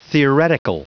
Prononciation du mot theoretical en anglais (fichier audio)
Prononciation du mot : theoretical